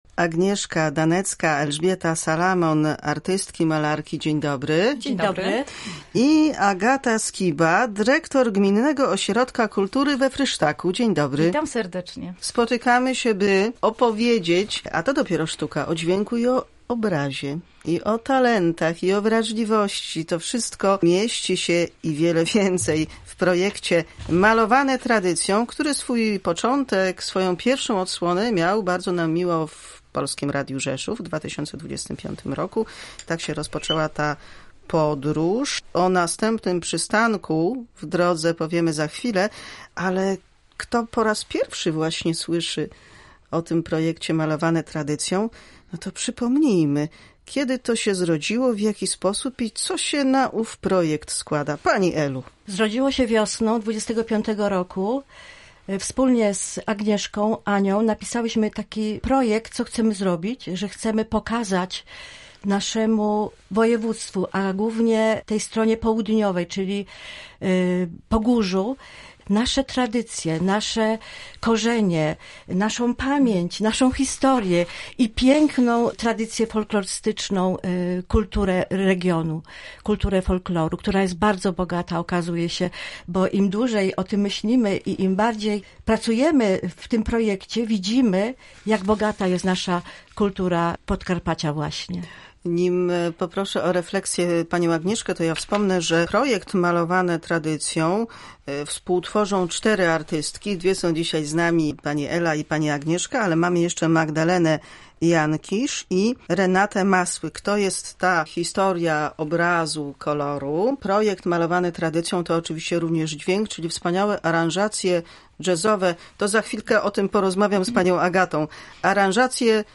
Projekt malarsko-muzyczny będzie miał swą odsłonę w Dom Polski Wschodniej. O szczegółach inicjatywy opowiadają goście audycji Wolna sobota.